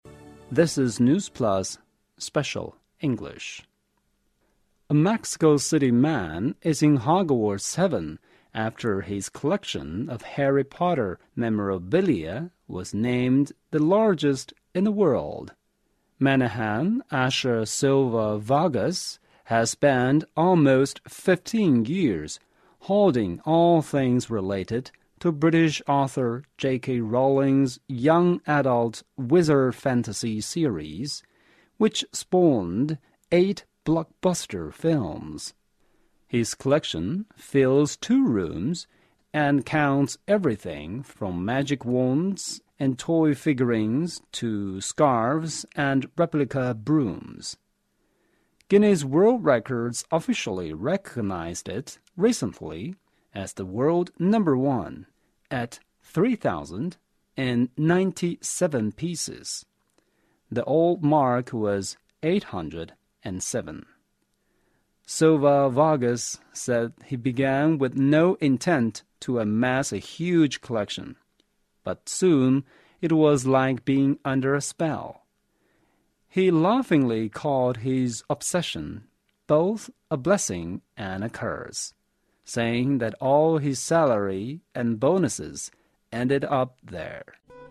News Plus慢速英语:墨西哥男子收集哈利波特纪念品成痴 破吉尼斯世界纪录